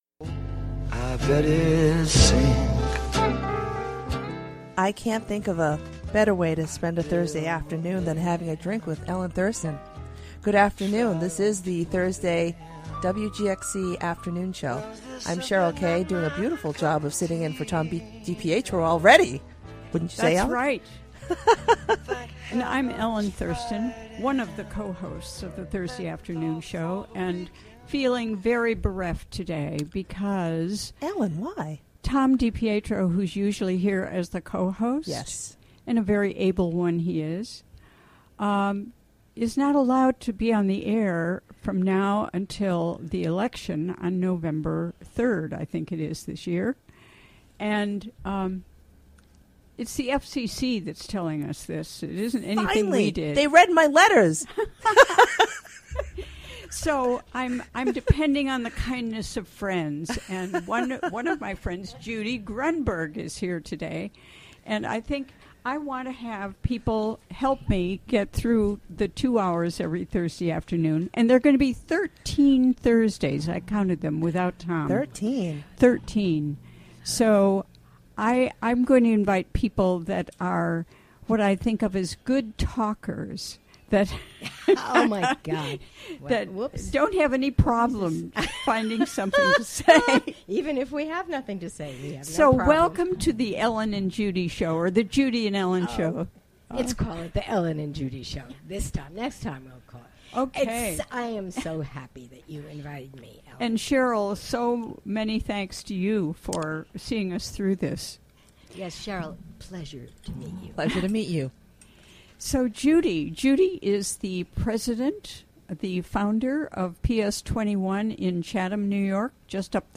Live, in-studio interview during the WGXC Afternoon Show.